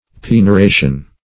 Pignoration \Pig`no*ra"tion\, n. [LL. pignoratio, L. pigneratio,